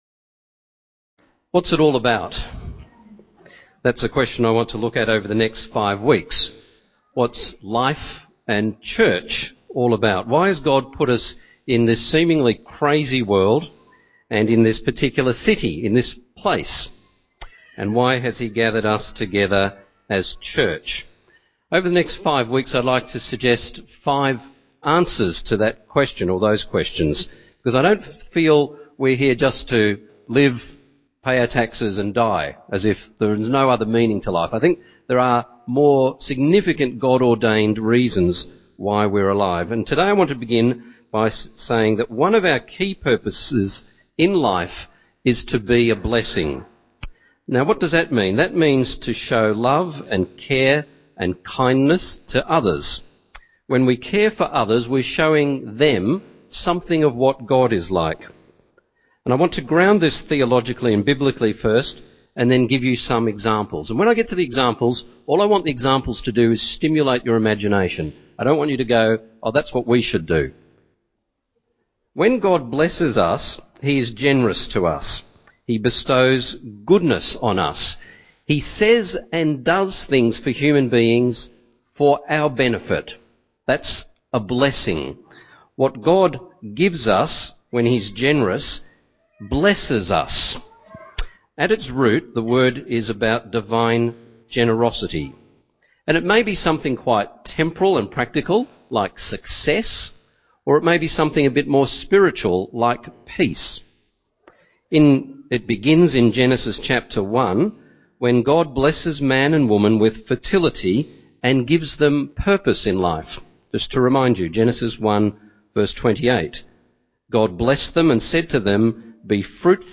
Download Download Bible Passage Genesis 12:1-3, Galatians 3:6-9 In this sermon